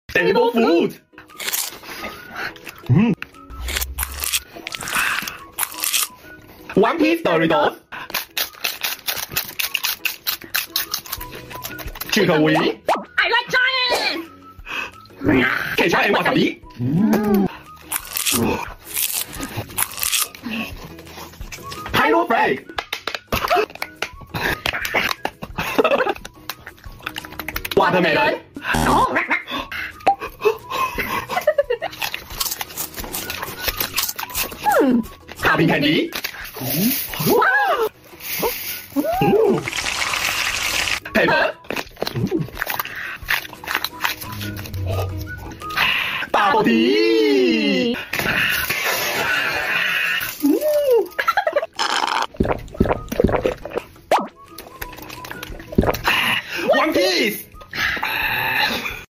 Random Green Red Food Mukbang sound effects free download
Random Green Red Food Mukbang ASMR🥵bubble tea boba🧋devil fruit